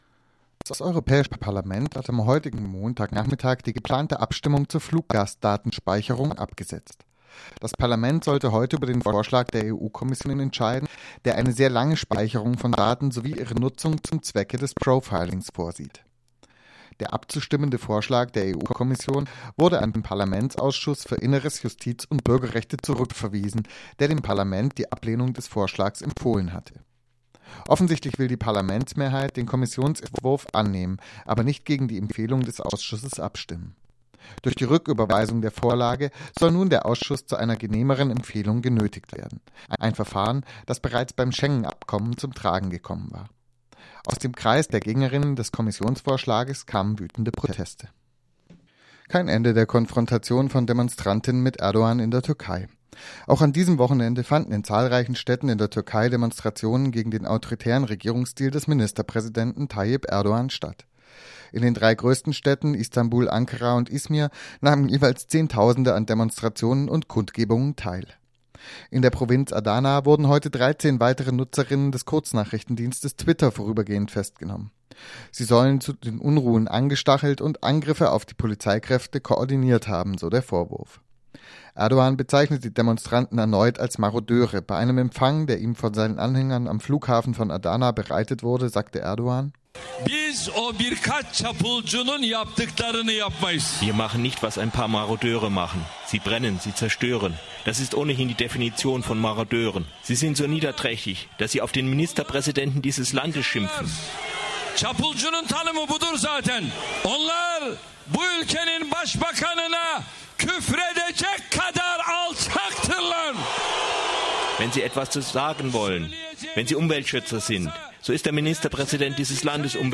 Focus Europa Nachrichten von Montag, den 10. Juni - 18 uhr